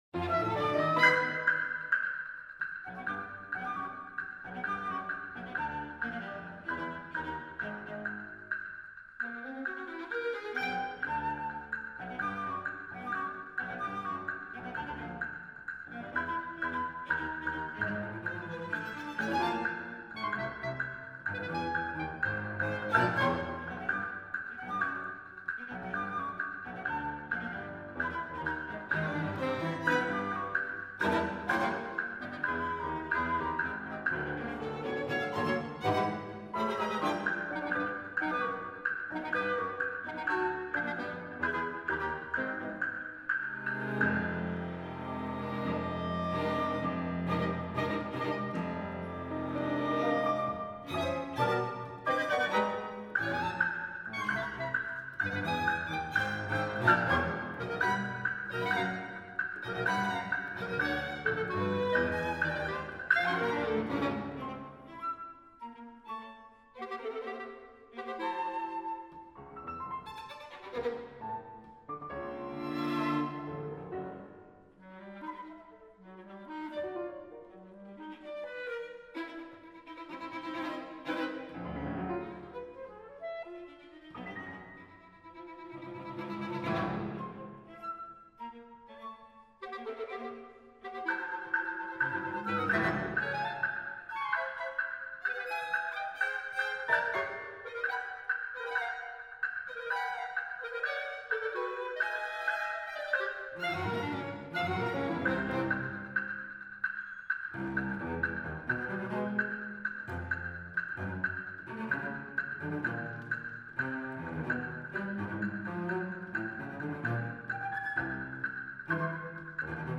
for flute, clarinet, percussion, piano, violin, viola, and cello
These gestures attempt to break free of the relentless driving force, ultimately becoming unified as a single more powerful entity.
Performance by the USC Contemporary Music Ensemble